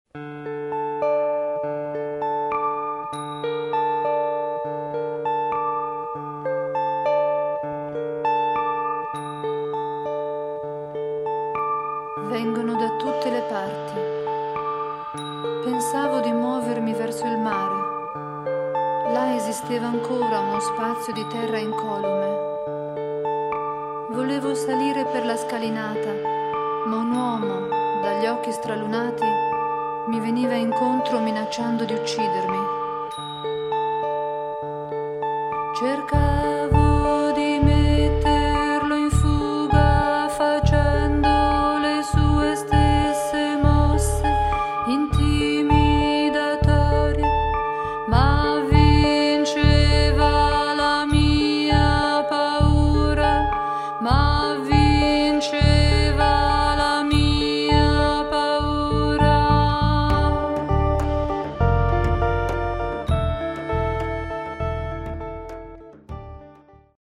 Gesang, Perkussion
Gitarre